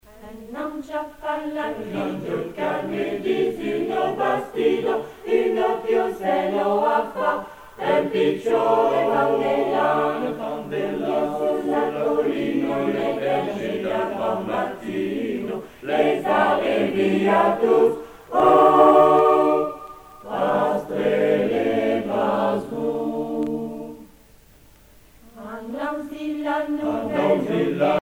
circonstance : Noël, Nativité
Pièce musicale éditée